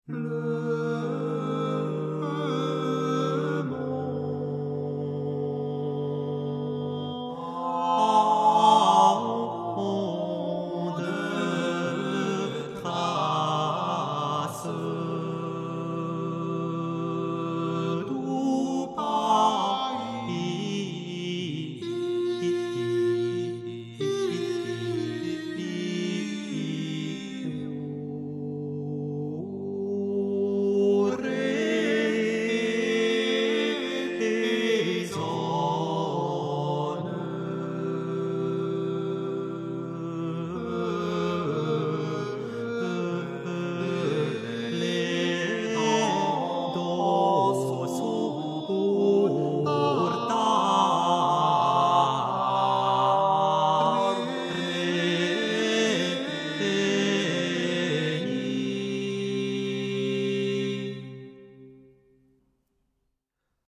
chant, santur
chant et rebec
flûtes, chalémie
percussions
vièle, oud, guiterne
vièle, lyra, ney, cornemuse